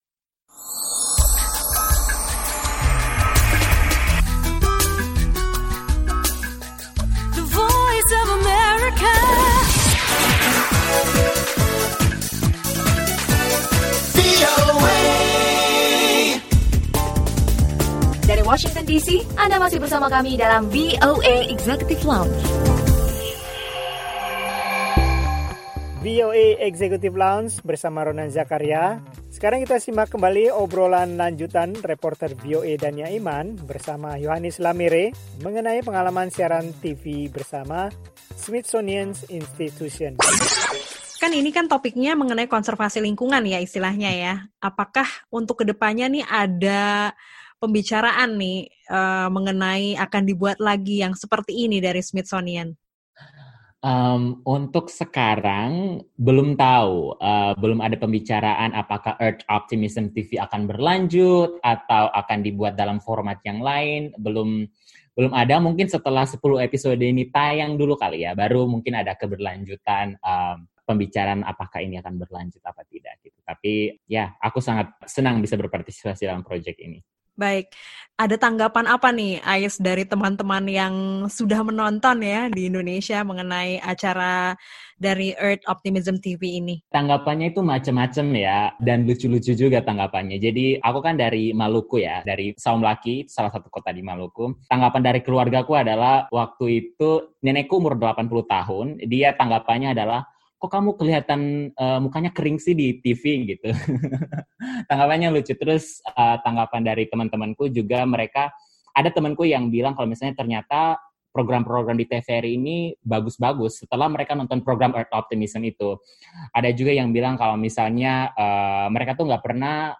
Obrolan lanjutan